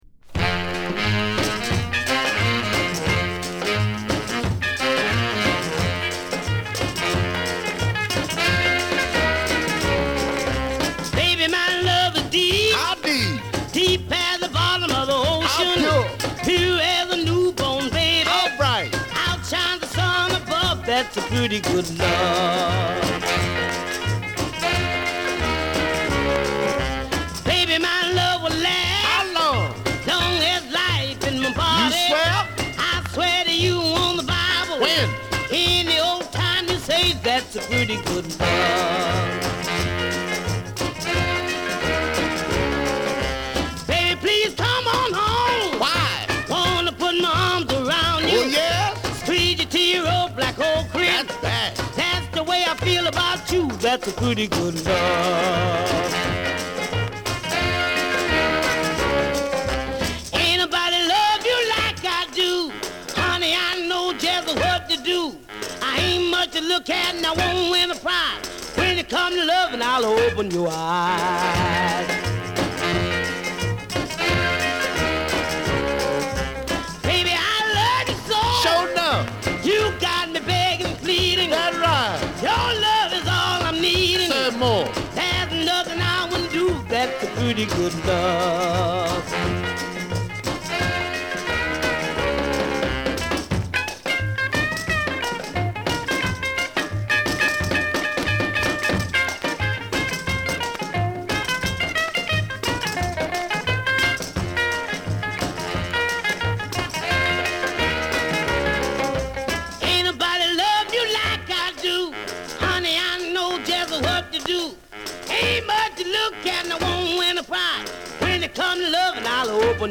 細かいスクラッチの影響で所々でノイズがはいります。